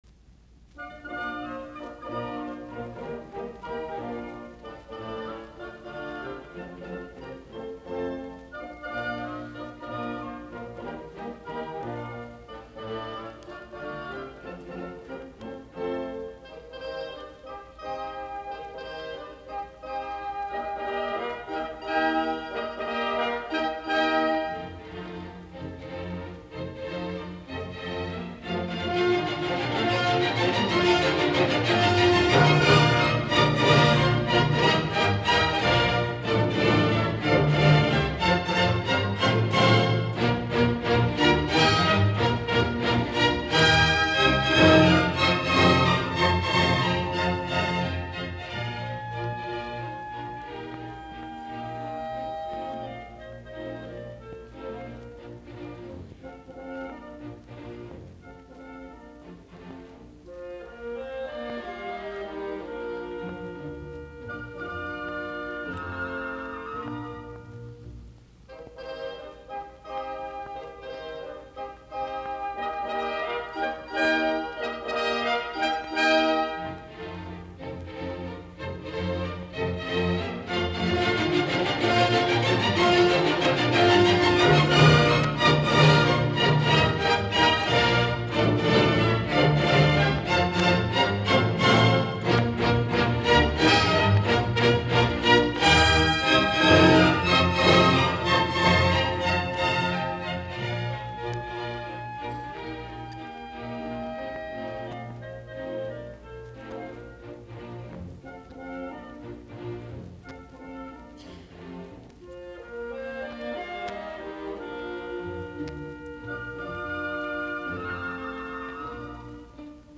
musiikkiäänite
Taltioitu radioidusta esityksestä 13.12.1953.